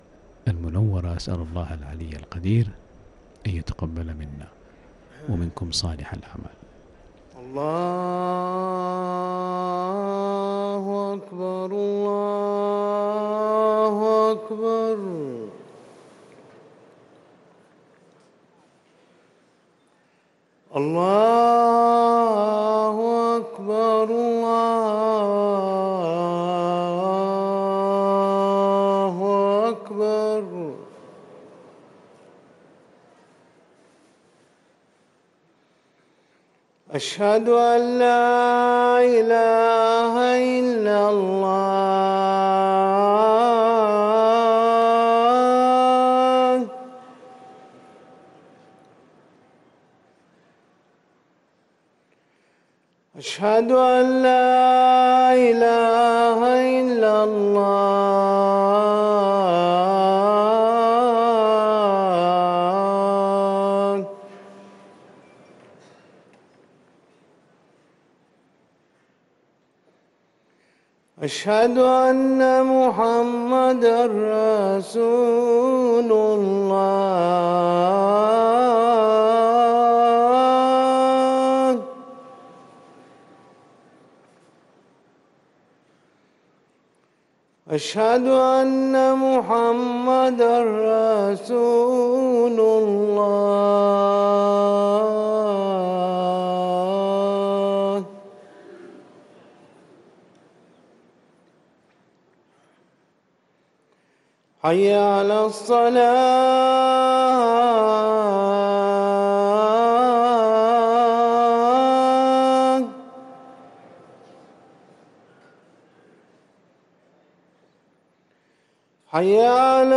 أذان المغرب للمؤذن